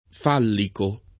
fallico [ f # lliko ]